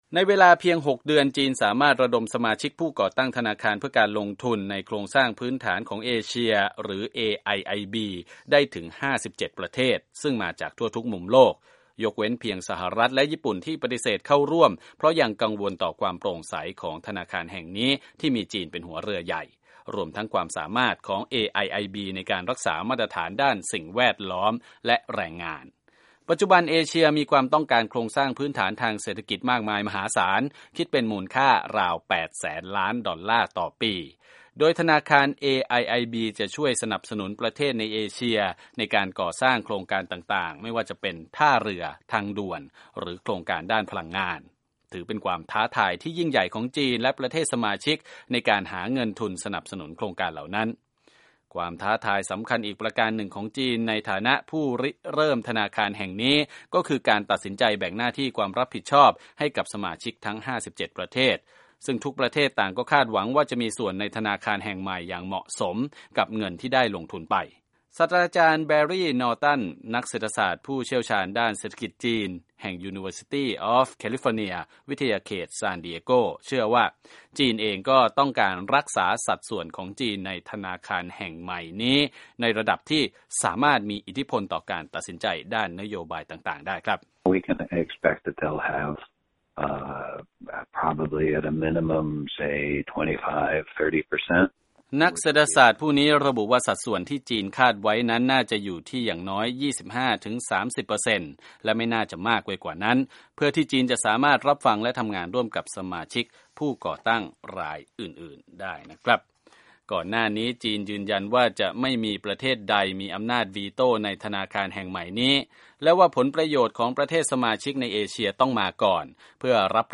รายงานจากห้องข่าววีโอเอ